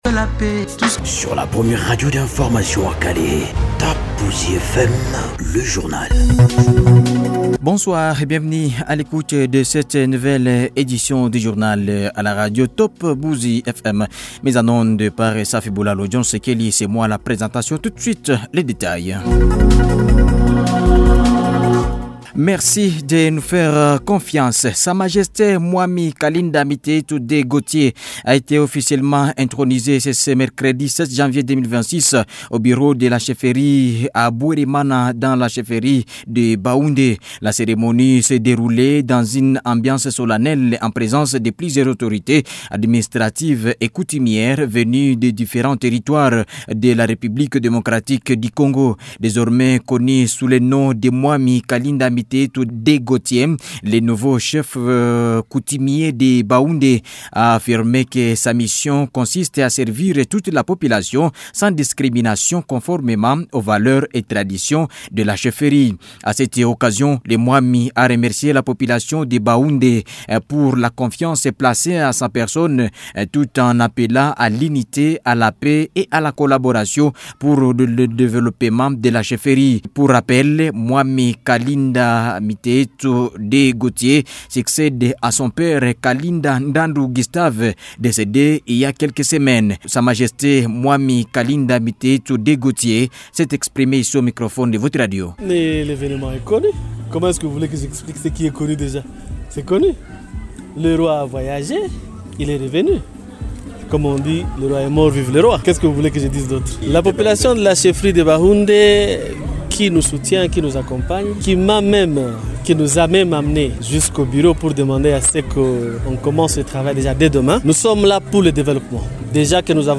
Journal soir 7 janvier 2026